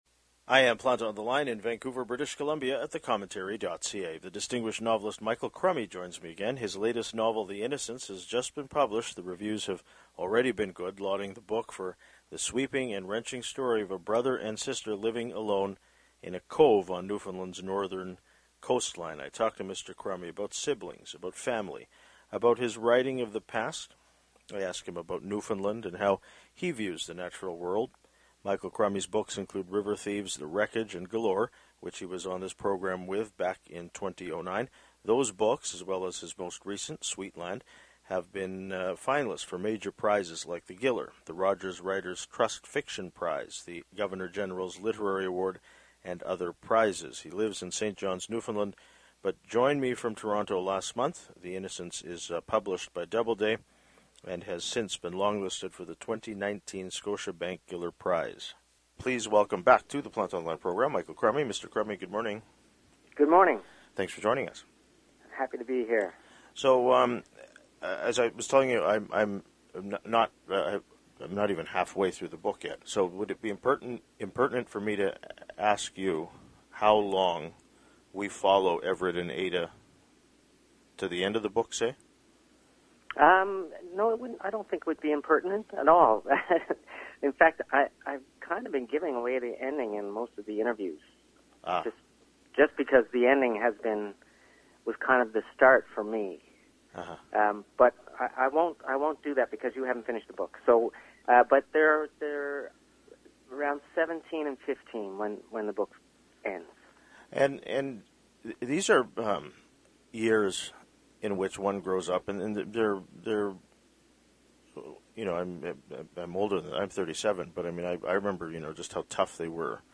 He lives in St. John’s, Newfoundland but joined me from Toronto last month.